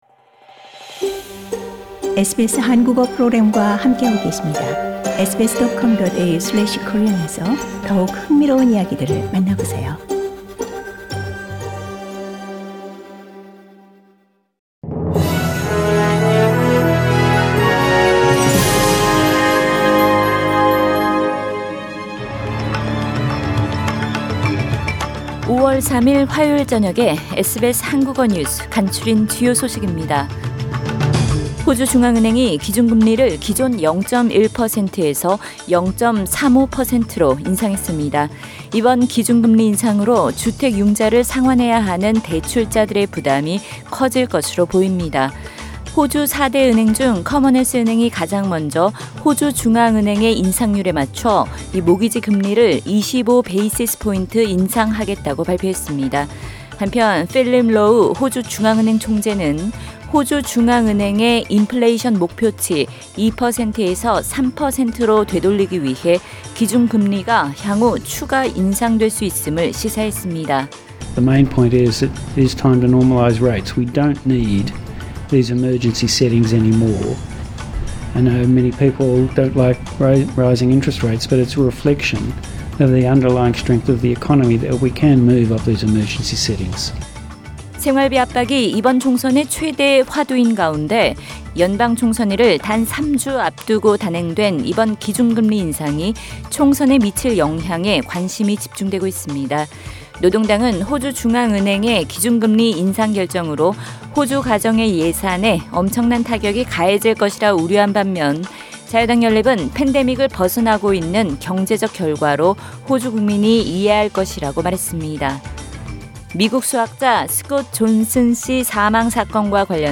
2022년 5월 3일 화요일 저녁 SBS 한국어 간추린 주요 뉴스입니다.